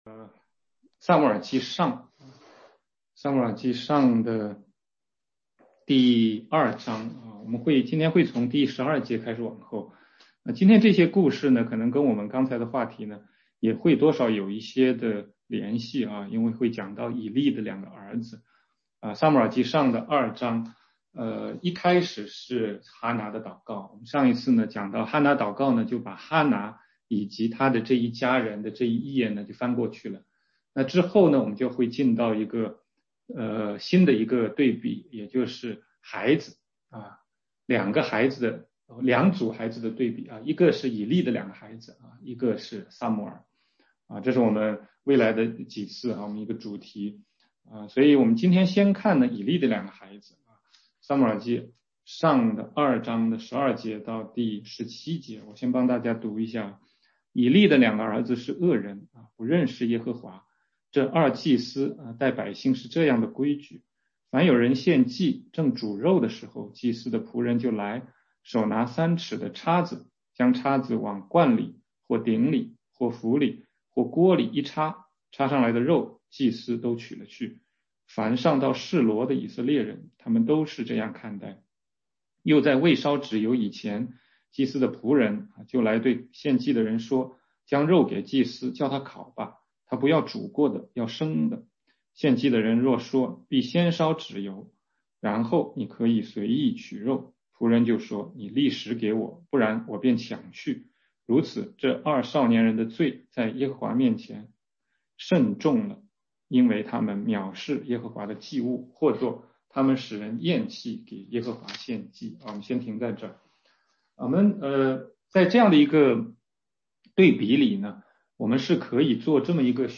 16街讲道录音 - 全中文查经